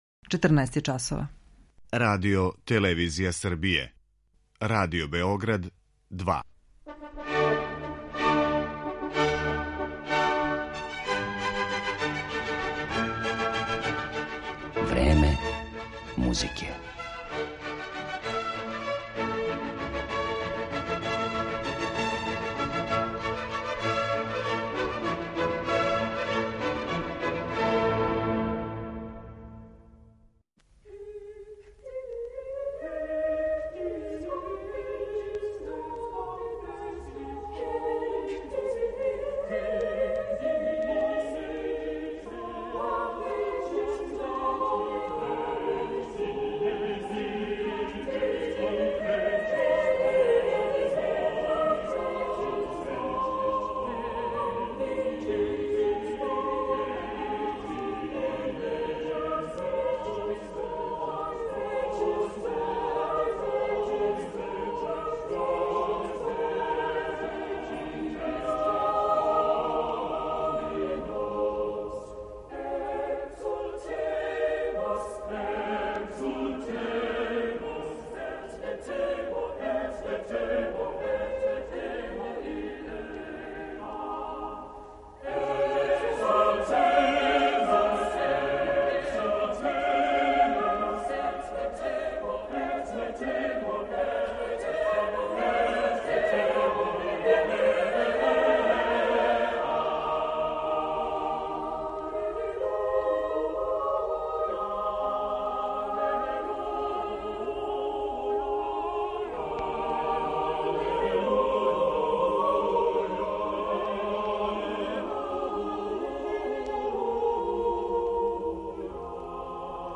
Овог истакнутог британског диригента и оргуљаша слушаћете претежно на челу ансамбла са којим је досегао врхунце у каријери и како изводи дела Вилијама Берда, Хајнриха Шица, Ђованија Пјерлуиђија да Палестрине, Јохана Себастијана Баха и Бенџамина Бритна.